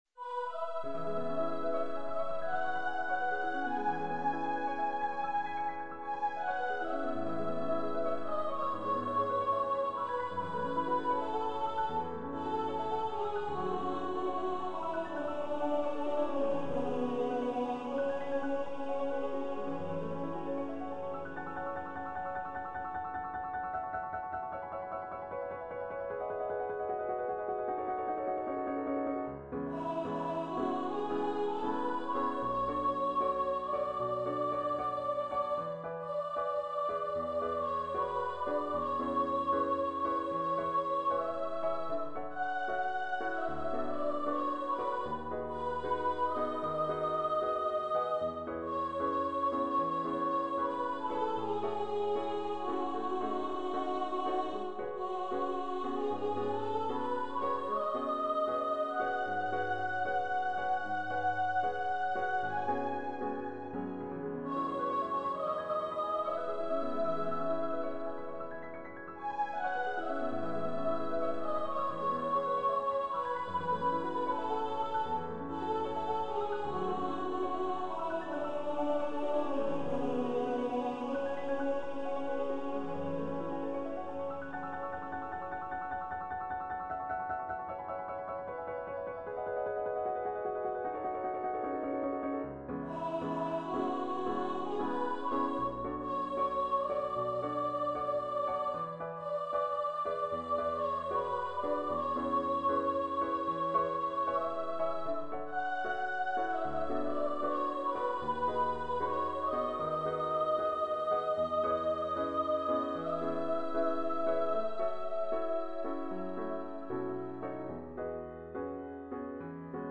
Voice, Piano
Composer's Demo